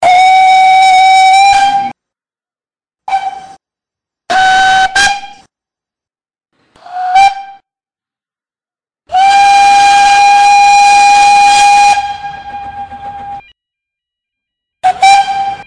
Recorded Whistles for Live Steam Locomotives
whistles_talyllyn.mp3